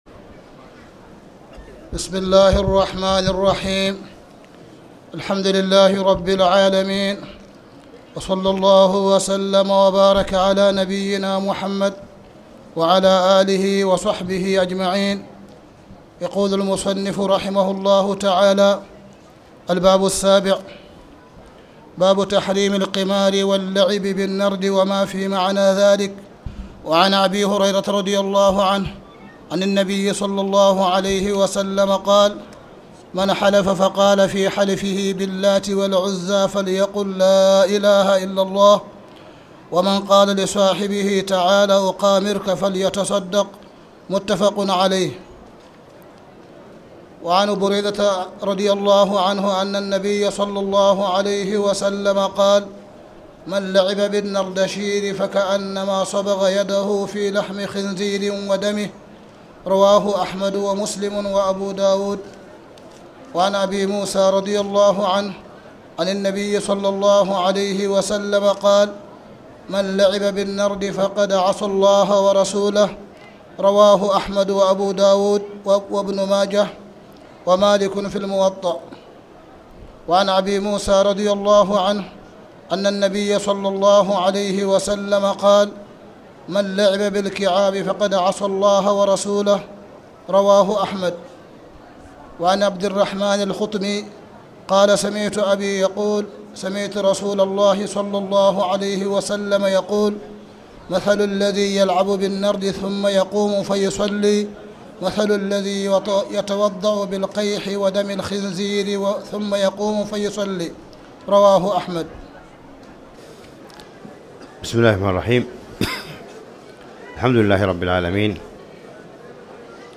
تاريخ النشر ٢٠ رمضان ١٤٣٨ هـ المكان: المسجد الحرام الشيخ: معالي الشيخ أ.د. صالح بن عبدالله بن حميد معالي الشيخ أ.د. صالح بن عبدالله بن حميد باب تحريم القمار واللعب بالنرد